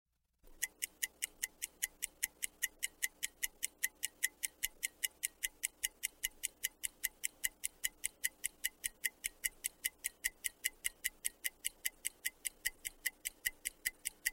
ticking-clock_25471.mp3